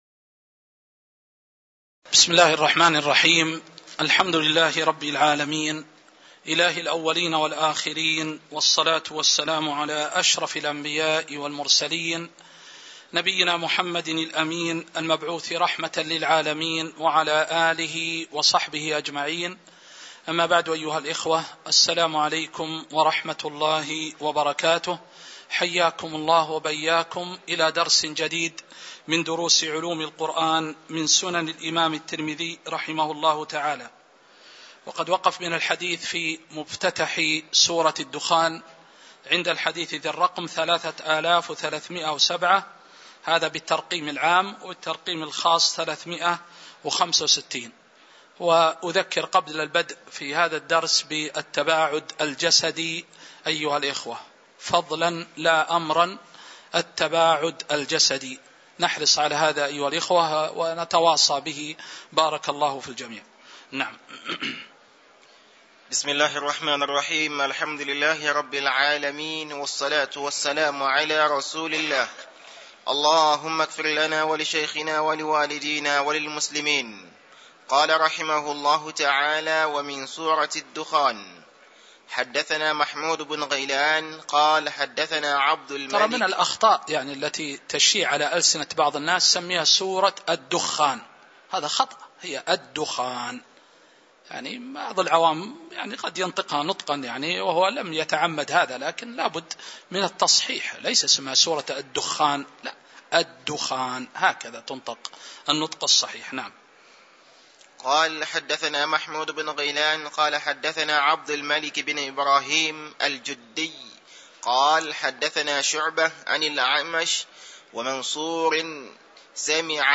تاريخ النشر ٦ رجب ١٤٤٣ هـ المكان: المسجد النبوي الشيخ